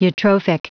Prononciation du mot : eutrophic
eutrophic.wav